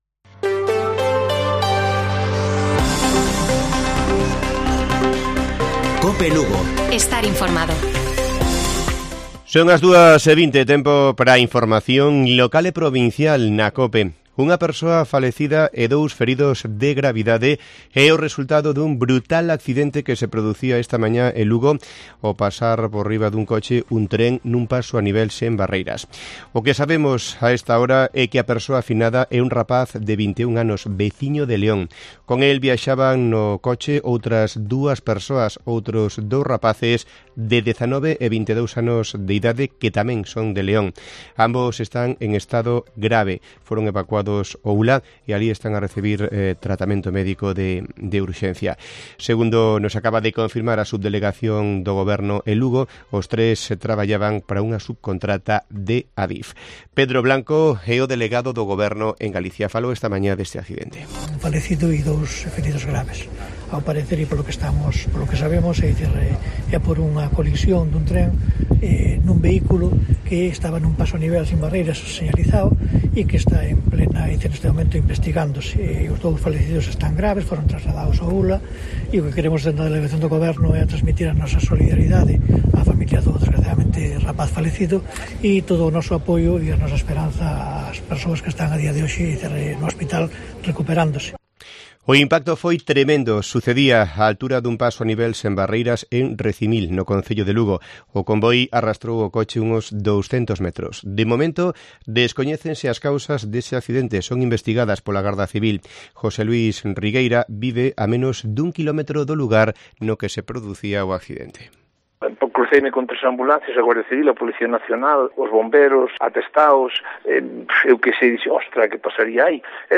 Informativo Mediodía de Cope Lugo. 12 DE JULIO. 14:20 horas